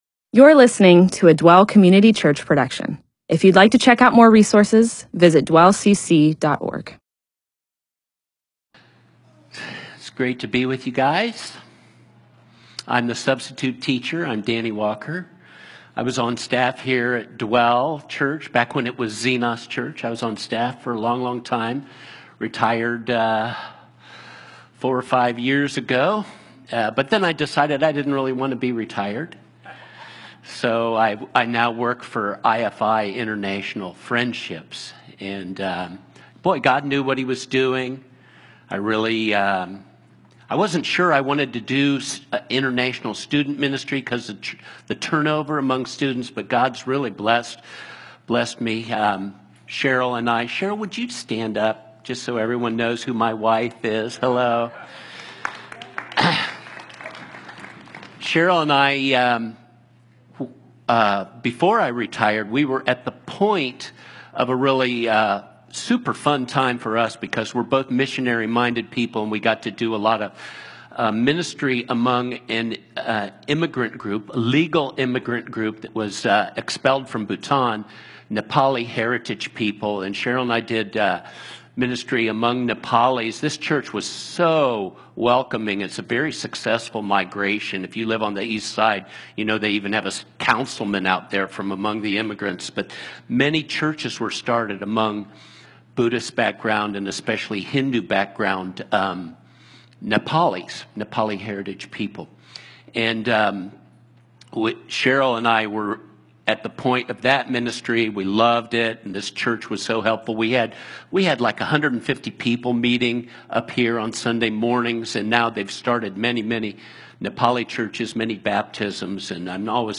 MP4/M4A audio recording of a Bible teaching/sermon/presentation about Exodus 13.